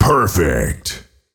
Фразы после убийства противника